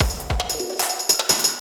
CRAZ BL LOOP 2.wav